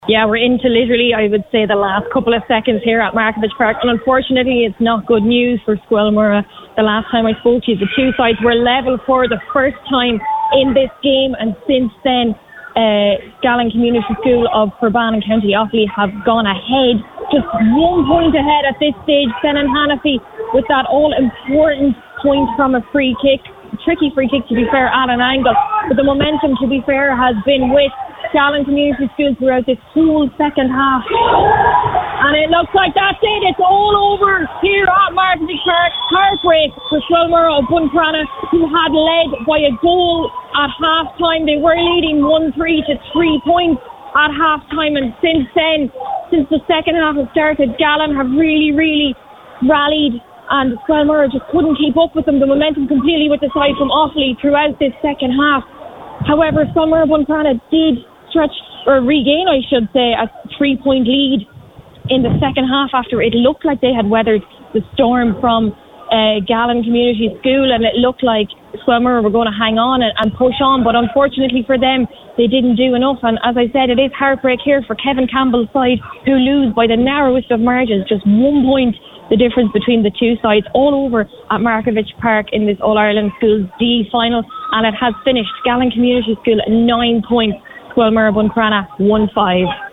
full time match report